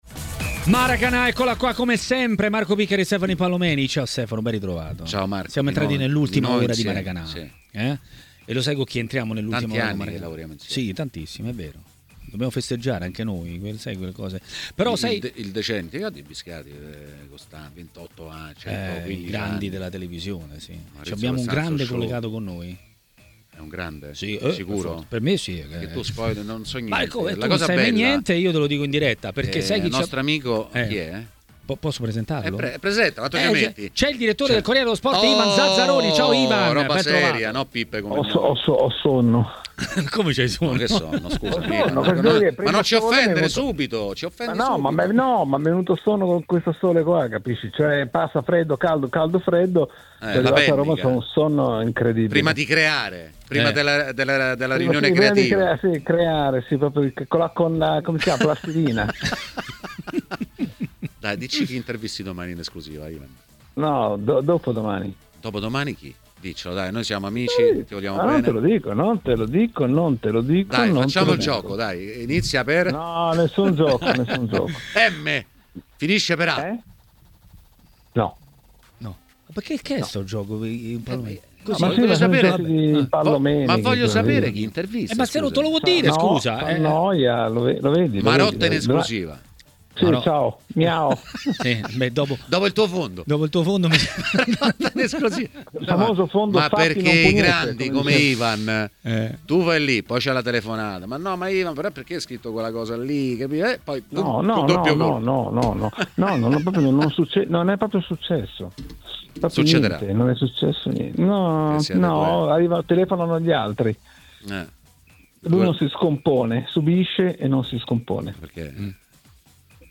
Il direttore de Il Corriere dello Sport Ivan Zazzaroni ha parlato ai microfoni di TMW Radio, durante Maracanà.